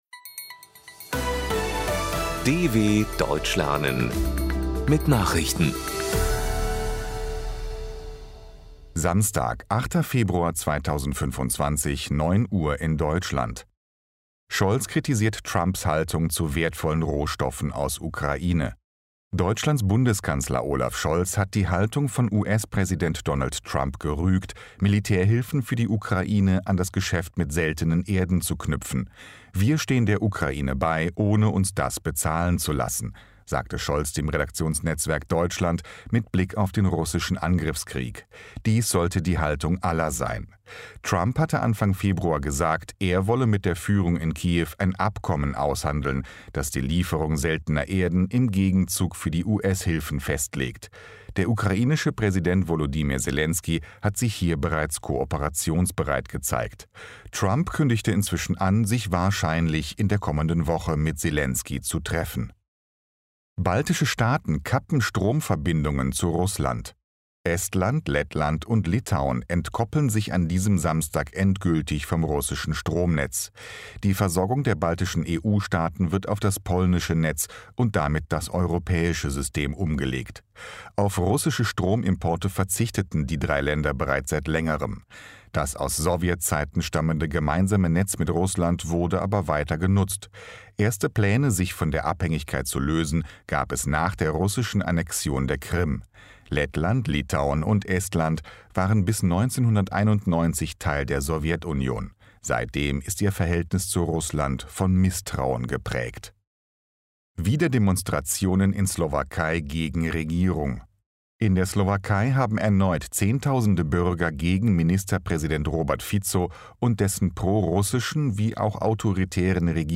08.02.2025 – Langsam Gesprochene Nachrichten
Trainiere dein Hörverstehen mit den Nachrichten der DW von Samstag – als Text und als verständlich gesprochene Audio-Datei.